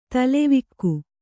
♪ talevvikku